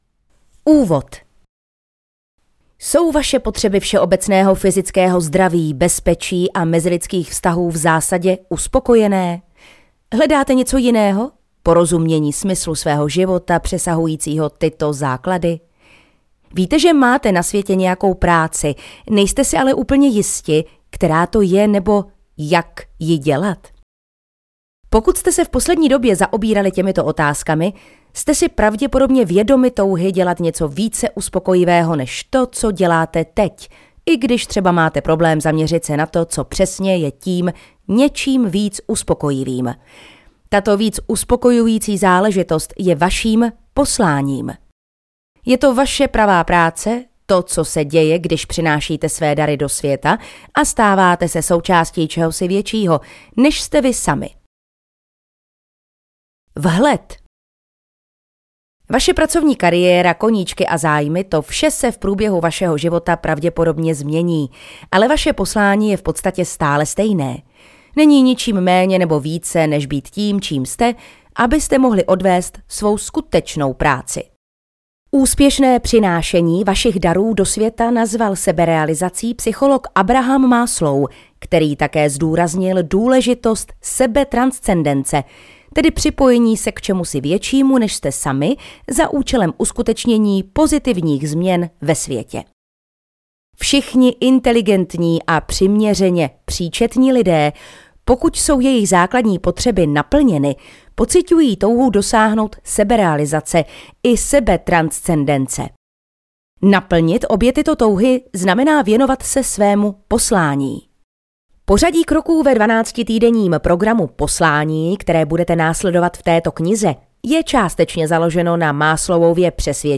Audiokniha Poslání - Julia Mossbridge | ProgresGuru
audiokniha